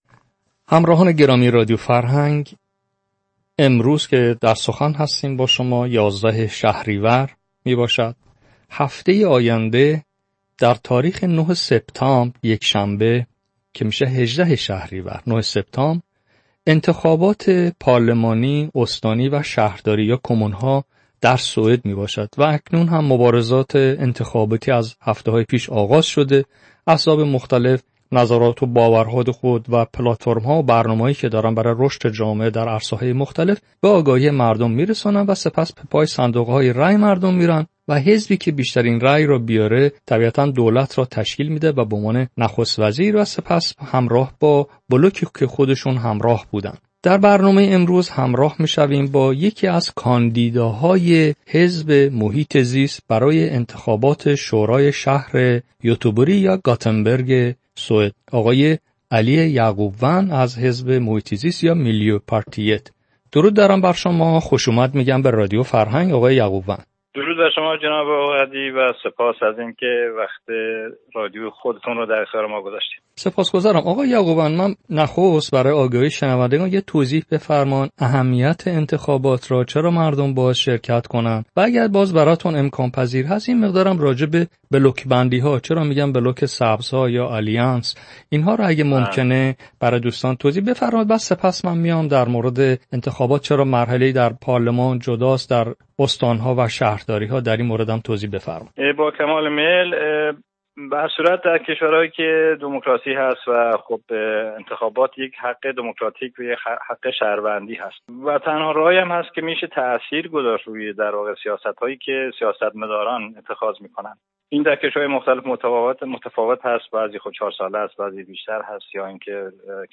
گفت و شنود